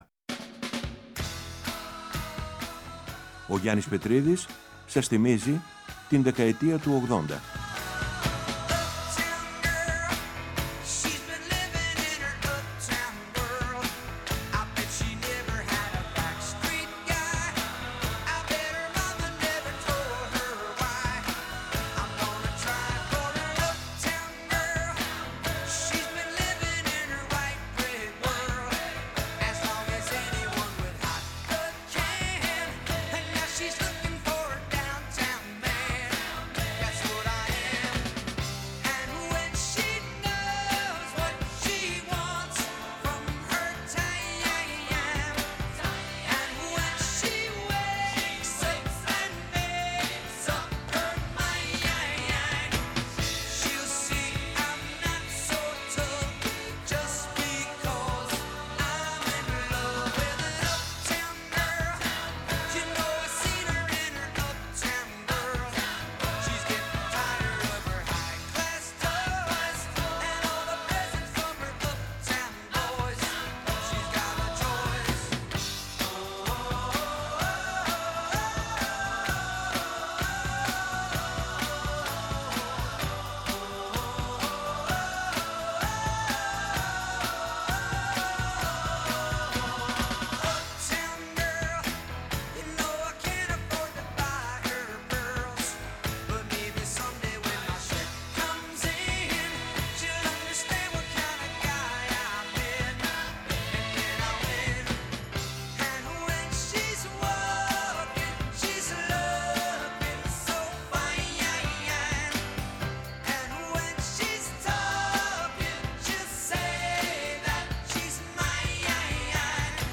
Ακολουθούν μία ανασκόπηση του grunge, του τελευταίου σπουδαίου μαζικού κινήματος του ροκ, καθώς και αφιερώματα στο post punk, το trip hop, τη house, την electronica, τη χορευτική μουσική και άλλα καθοριστικά μουσικά είδη της συγκεκριμένης 20ετίας.